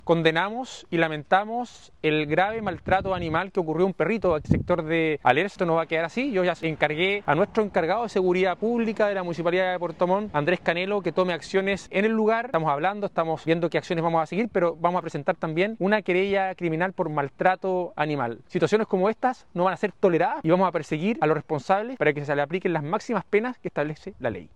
Por ello, ante el último caso ocurrido en el sector de Alerce, el Alcalde de Puerto Montt, Rodrigo Wainraihgt, dijo que presentarán una querella criminal por crueldad animal.